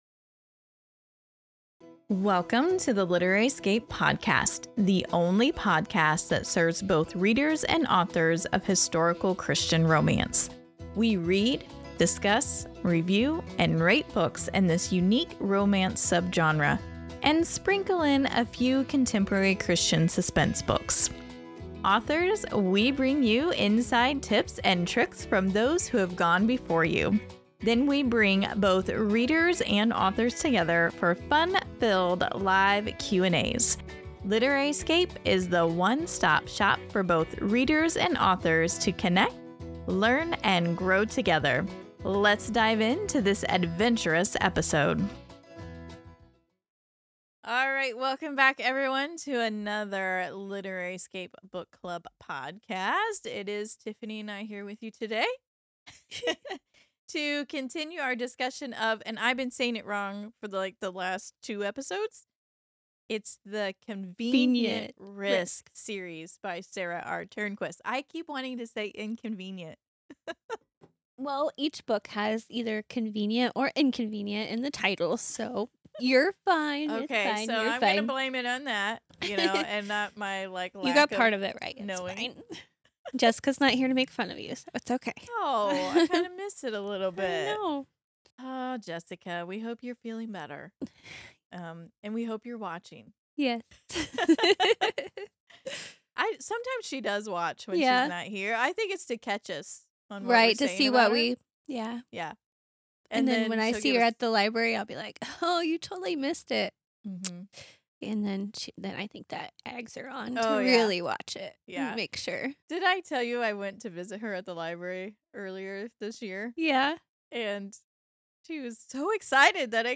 Episode 191: A Less Convenient Path Book Review | Christian Historical Western Romance | Convenient Risk Series Book 3 by Sara R. Turnquist